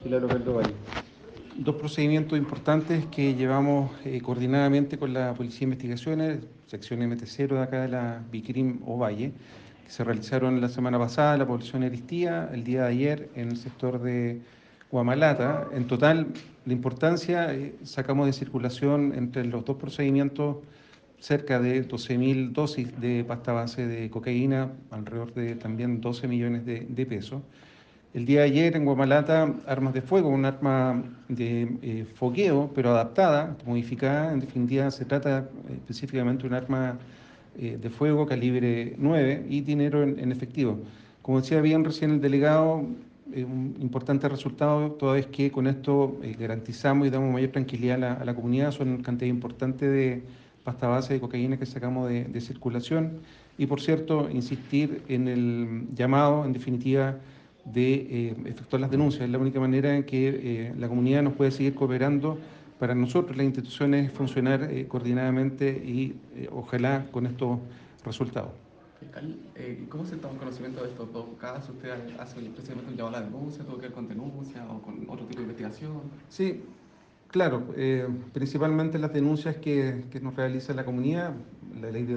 FISCAL-OVALL.mp3